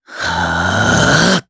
Evan-Vox_Casting4_jp_b.wav